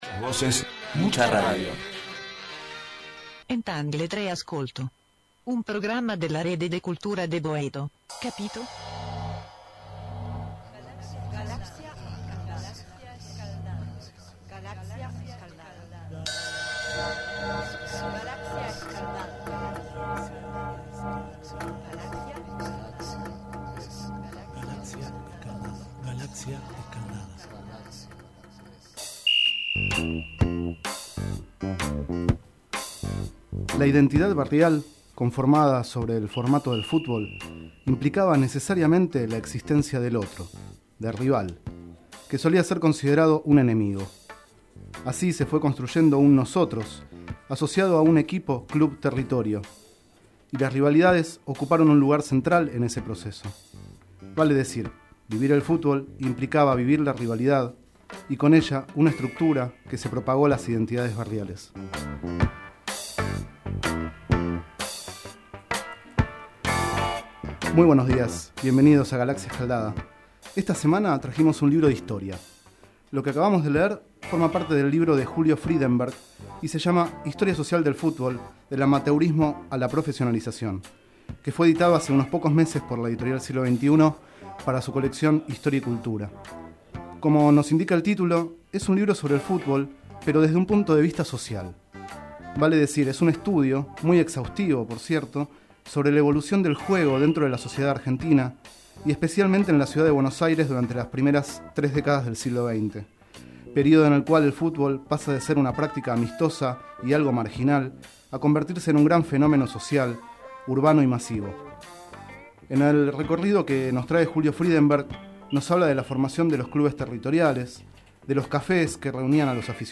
30º micro radial, realizado el 8 de octubre de 2011, sobre el libro Historia social del fútbol, de Julio Frydenberg.